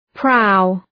Προφορά
{praʋ}